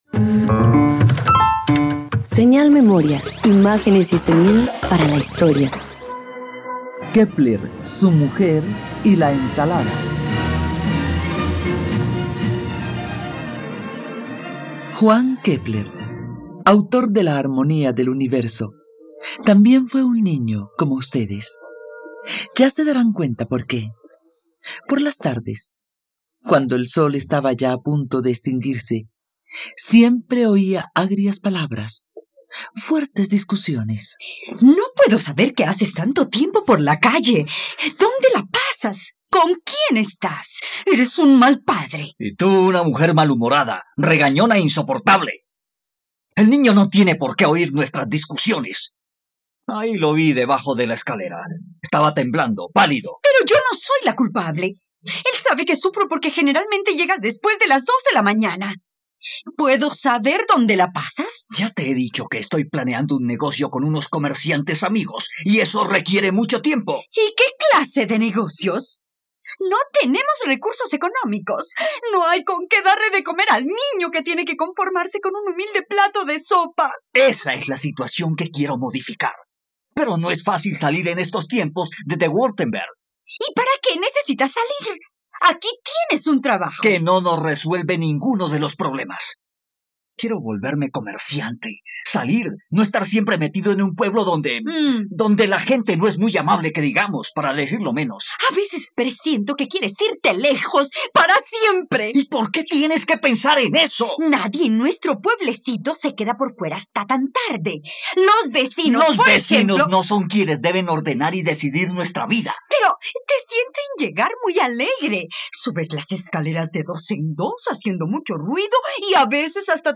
..Radioteatro. Disfruta una adaptación radiofónica de la vida del astrónomo y matemático alemán Johannes Kepler.